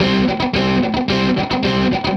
AM_HeroGuitar_110-C02.wav